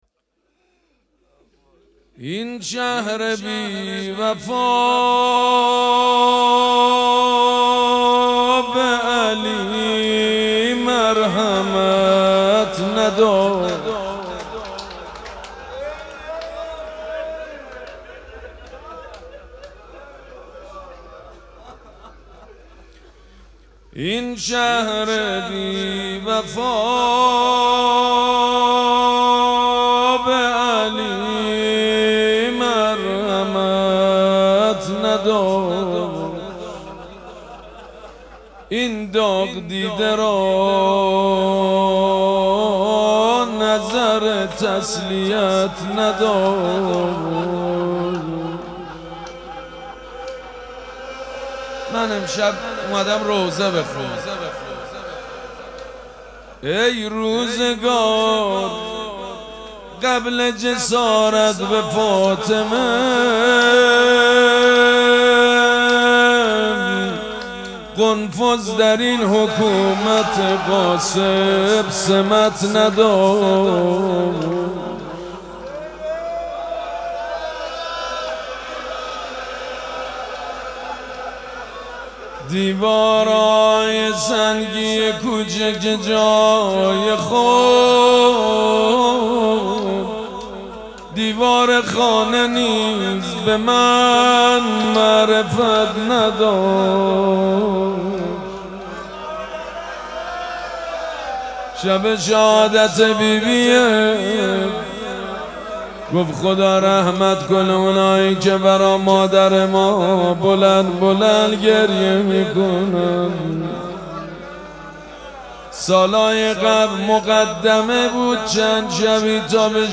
مراسم عزاداری شب شهادت حضرت زهرا سلام‌الله‌علیها
حسینیه ریحانه الحسین سلام الله علیها
روضه